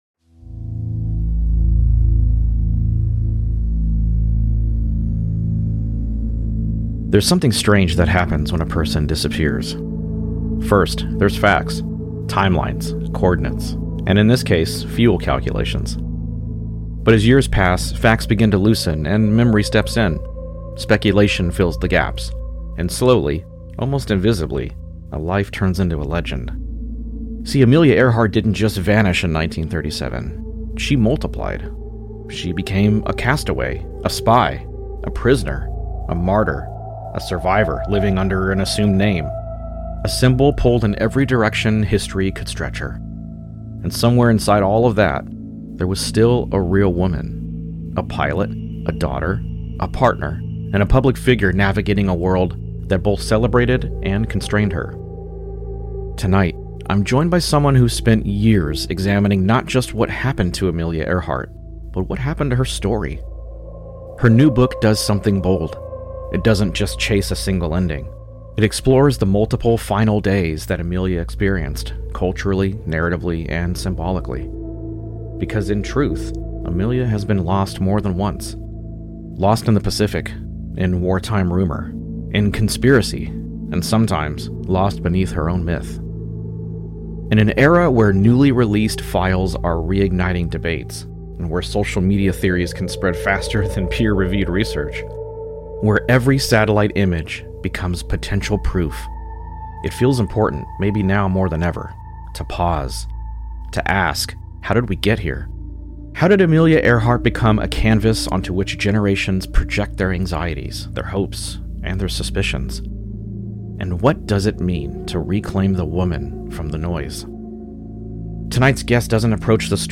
Lost: A Conversation